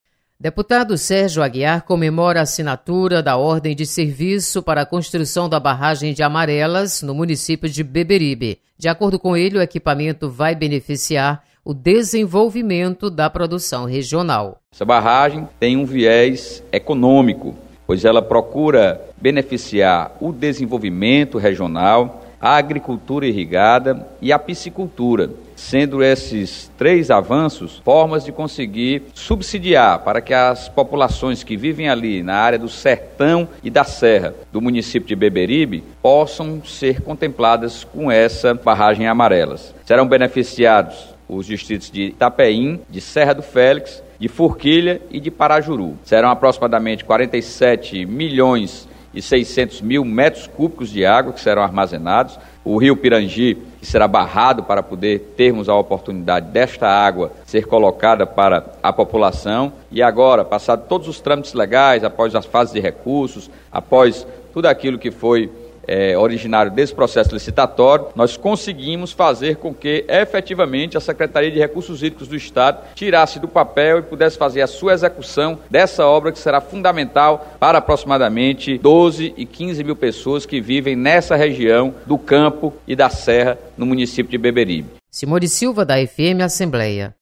Deputado Sérgio Aguiar destaca construção de barragem em Beberibe. Repórter